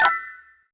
UI_SFX_Pack_61_22.wav